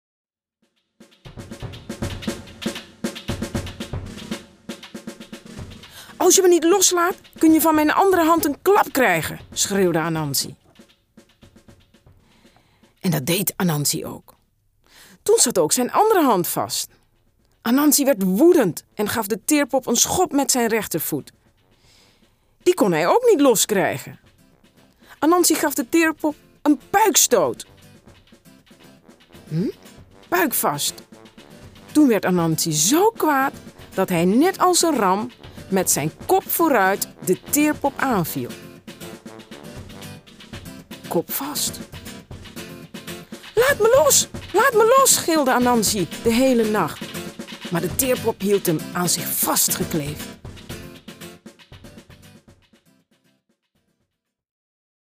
De Drakenkoning is een Nederlandstalige CD met kinderverhalen uit verschillende culturen